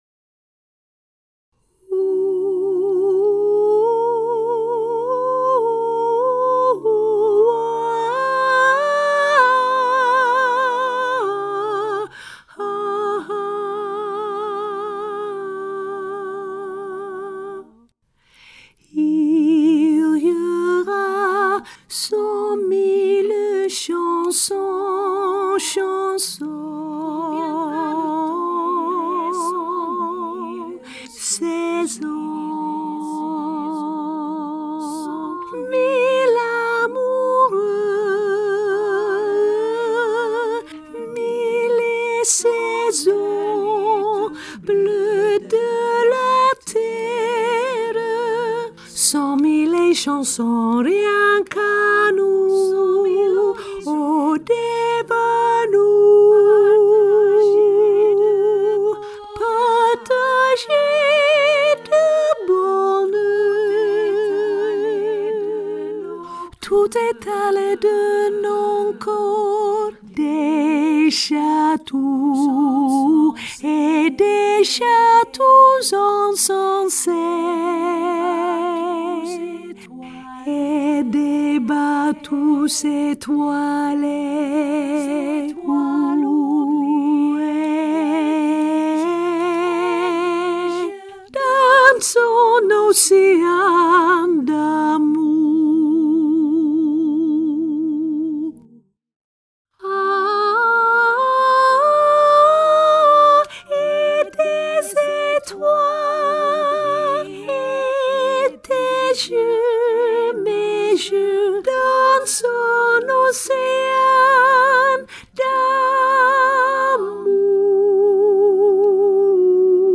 mezzo sopraan